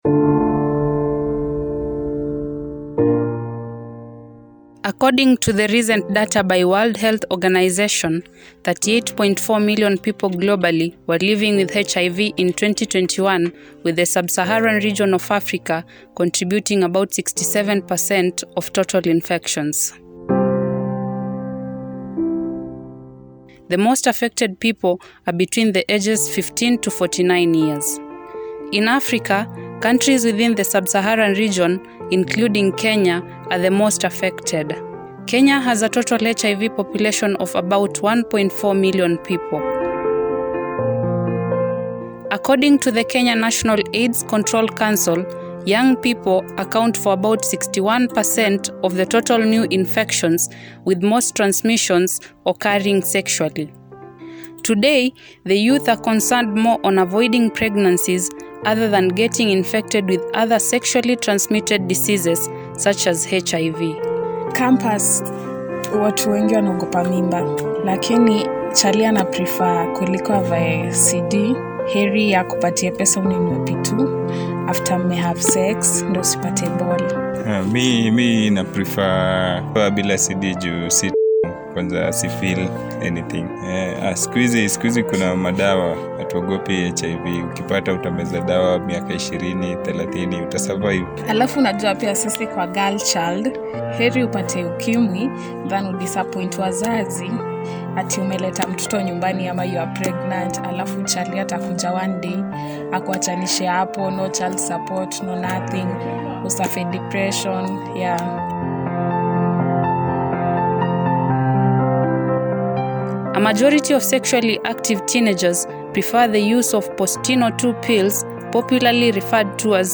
RADIO DOCUMENTARY;1.4M Kenyans Living With HIV, Youth shun Condoms for “P-2” Pills!
RADIO-DOCUMENTARY1.4M-KENYANS-LIVING-WITH-HIV.mp3